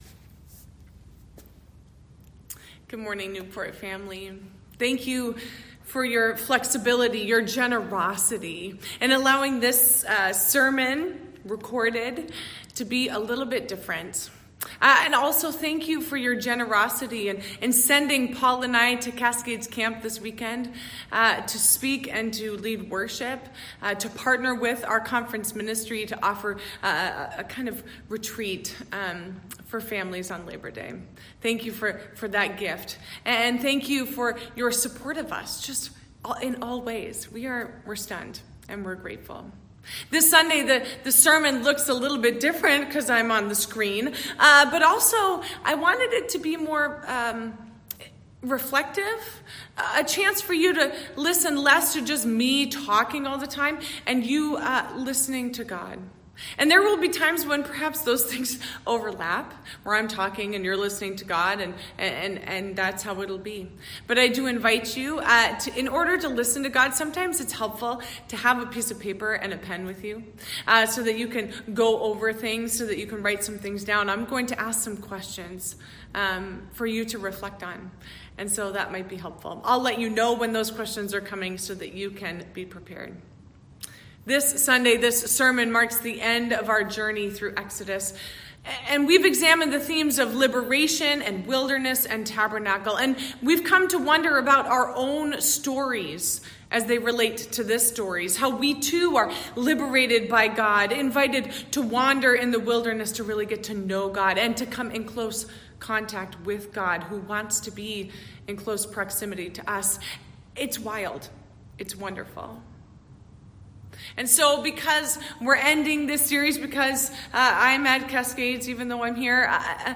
Service